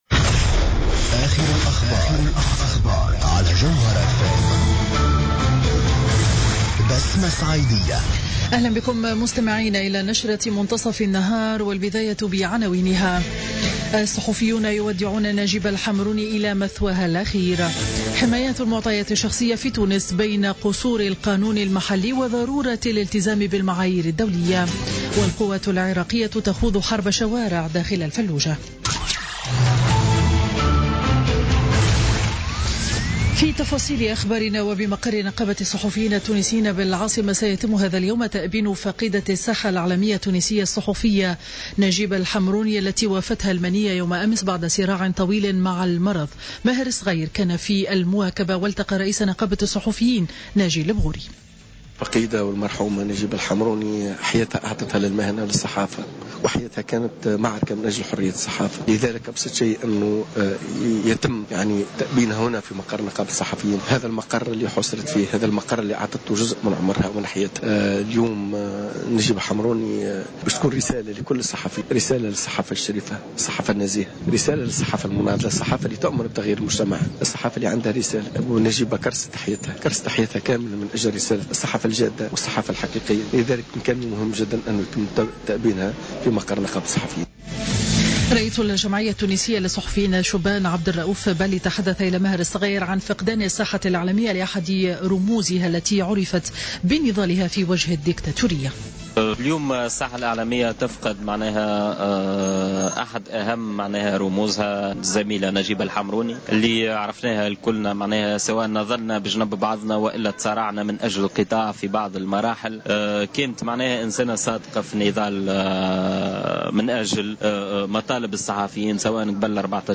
نشرة أخبار منتصف النهار ليوم الاثنين 30 ماي 2016